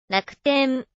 This is very different from the original Japanese pronunciation, where the -u- is weakened between k and t, becoming ‘devoiced’ and almost inaudible. Also, this word lacks a Japanese ‘pitch accent’, which means that the final vowel has the highest pitch and sounds the most prominent to speakers of languages like English. Here it is on Google Translate: